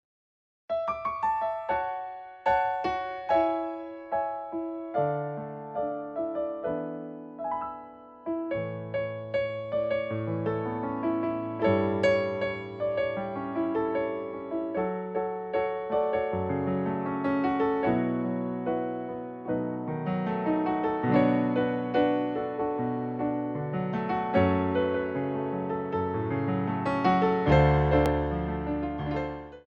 Show Tunes for Ballet Class
4/4 (16x8)